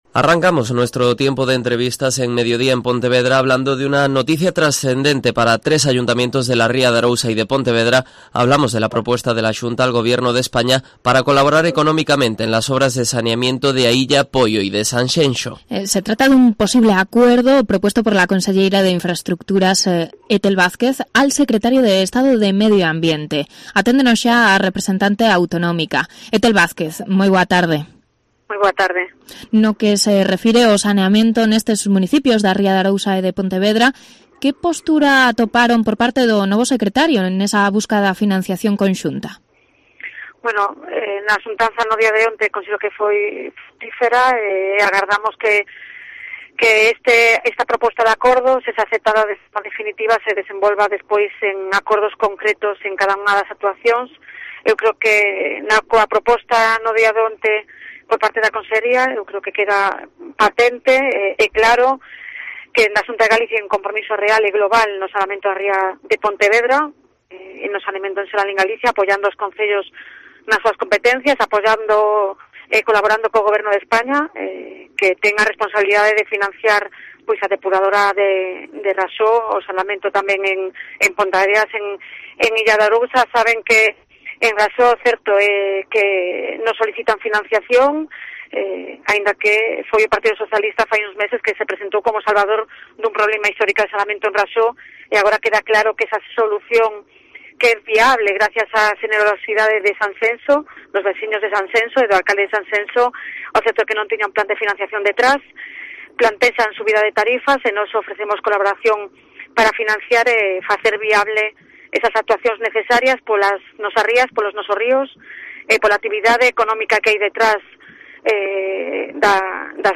Entrevista de Ethel Vázquez en Cope Pontevedra